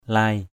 /laɪ/ 1.